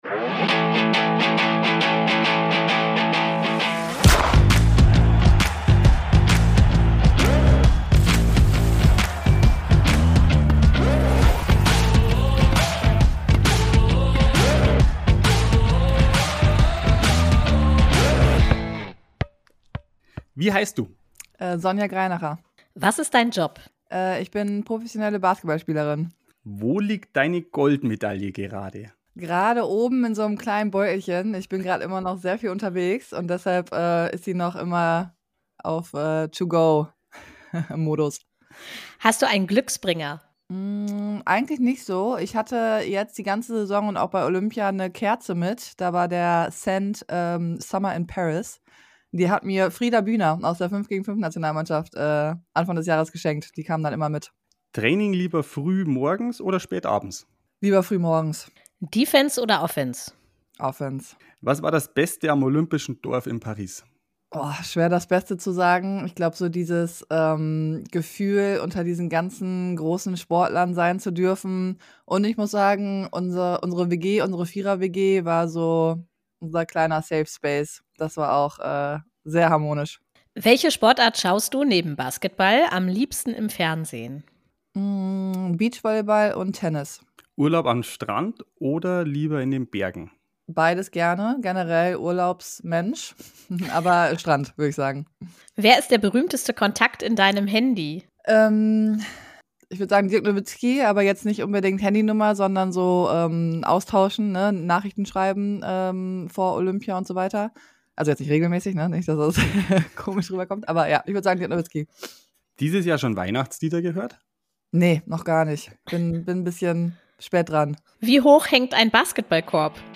Im Podcast spricht sie mit deinen Hosts